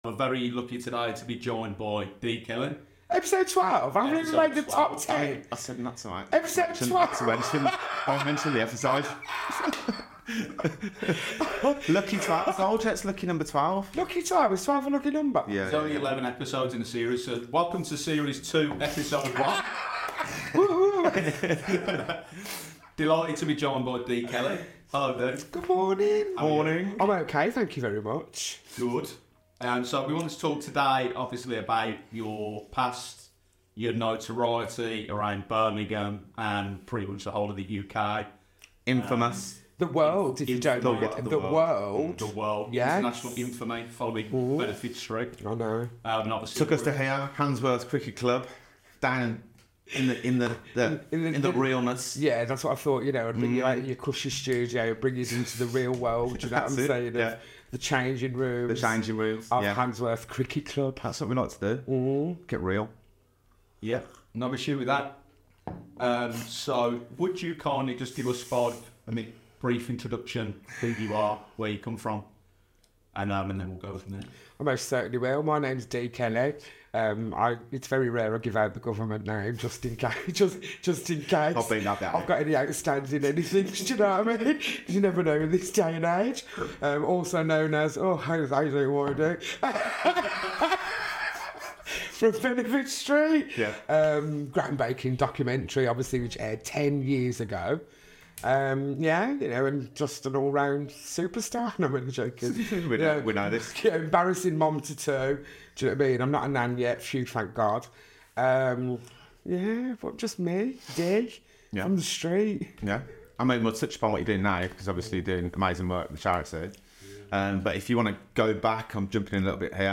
She talks about the impact 'Benefit Street' had on James Turner Street, her journey to the top 5 of Celebrity Big Brother, and her dedicated charity work fighting against knife crime in Birmingham. Don't miss this inspiring and eye-opening conversation!